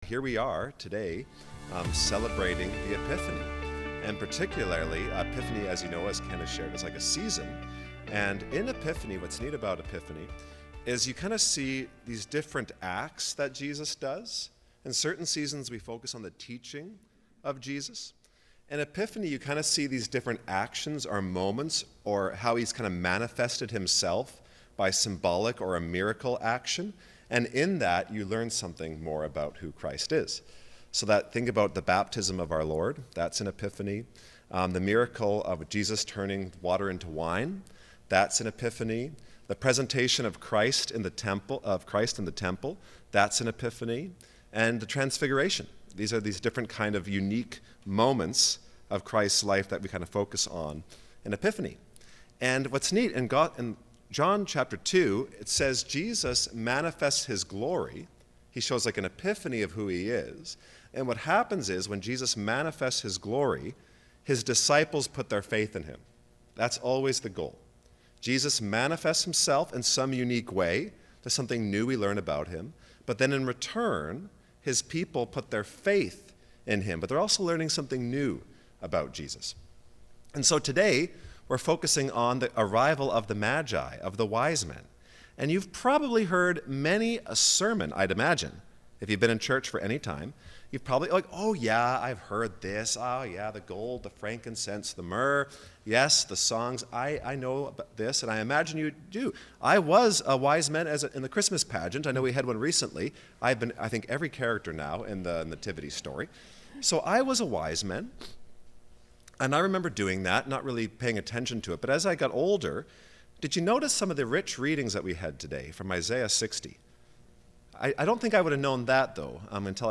Sermons | Church of the Ascension